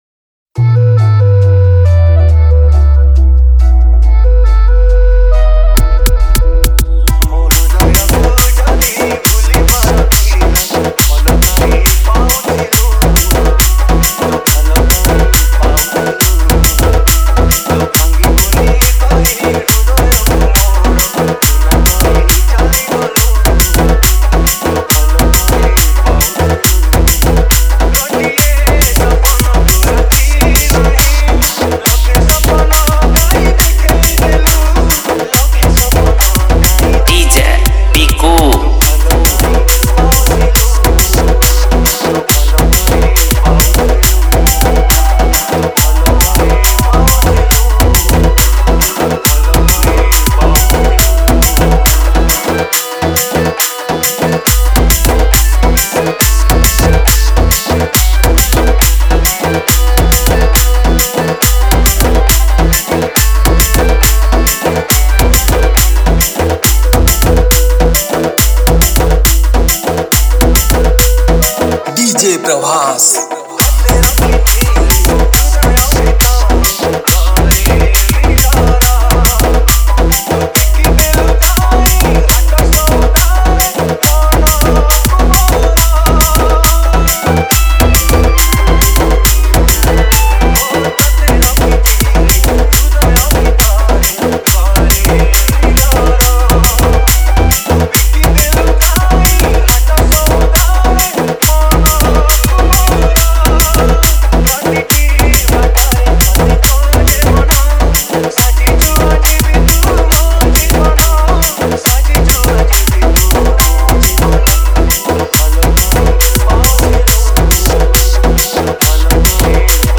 Category:  New Odia Dj Song 2020